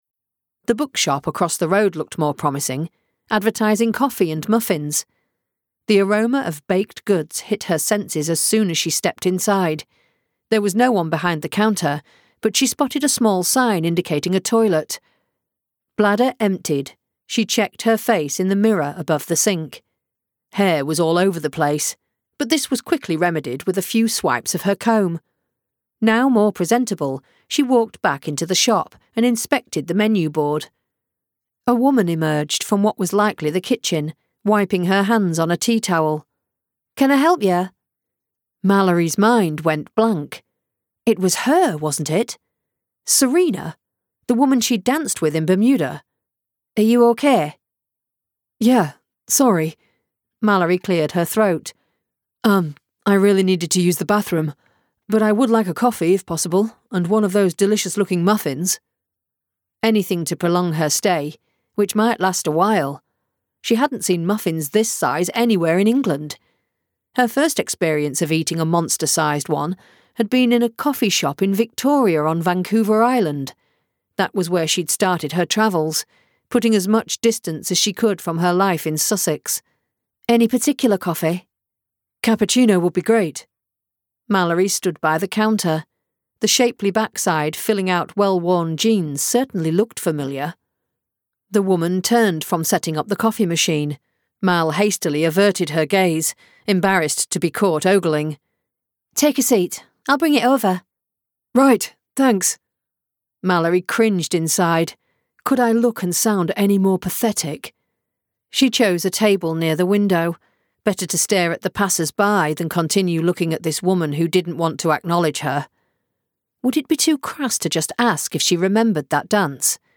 A Wild Moon Rises by Jen Silver [Audiobook]